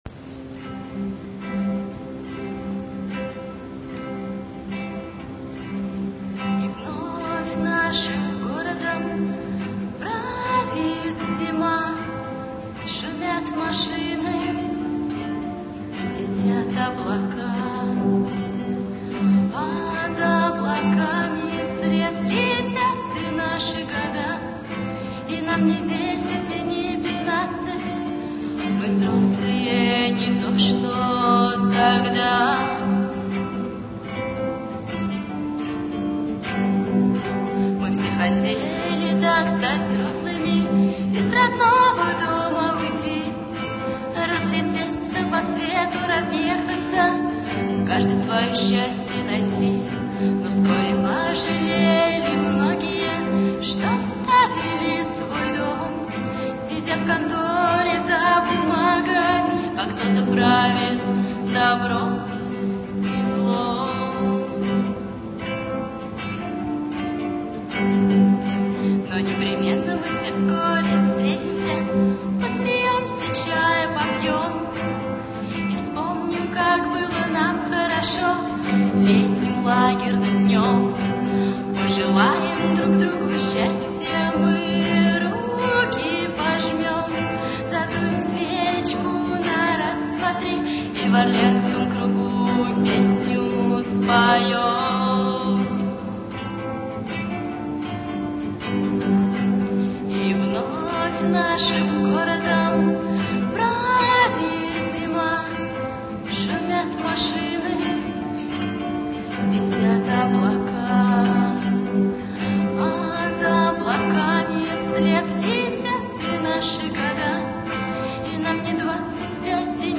Бардовский концерт
декабрь 2000, Гимназия №45